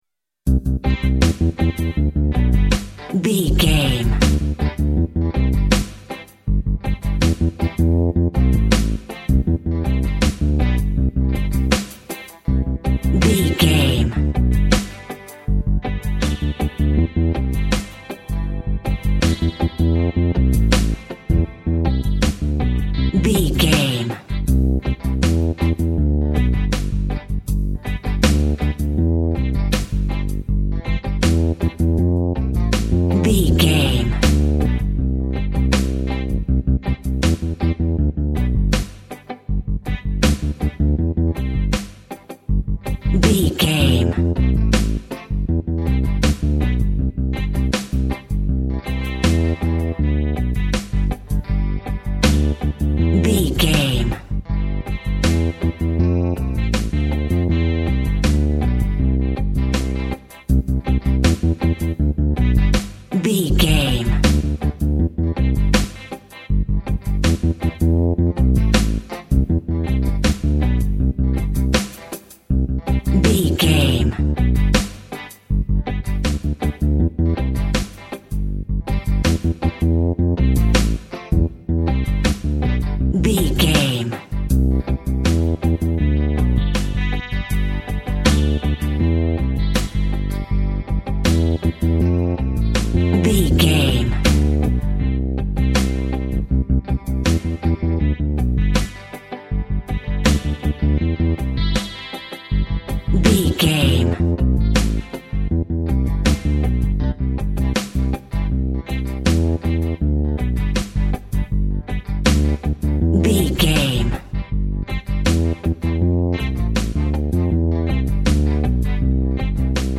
Ionian/Major
Slow
tropical
drums
bass
guitar
piano
brass
pan pipes
steel drum